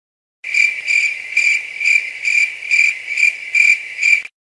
Fx Grillo Sound Button - Free Download & Play